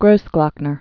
(grōsglŏknər)